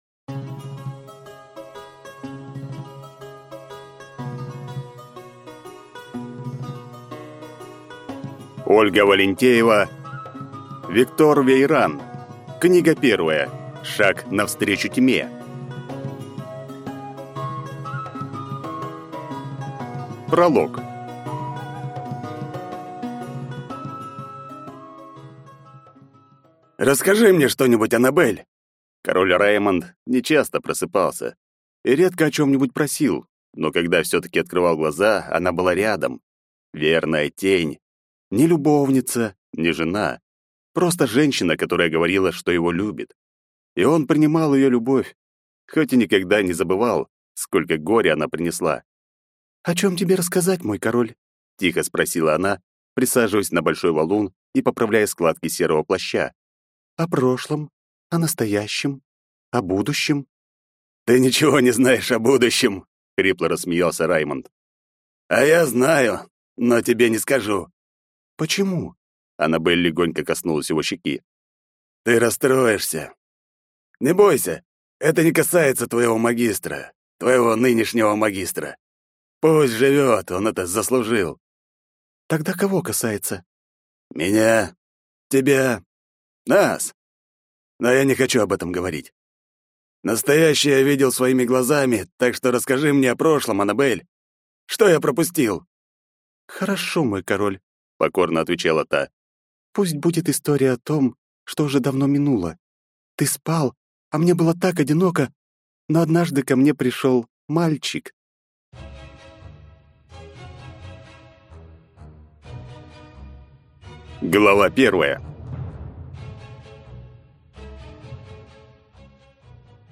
Аудиокнига Шаг навстречу тьме | Библиотека аудиокниг
Прослушать и бесплатно скачать фрагмент аудиокниги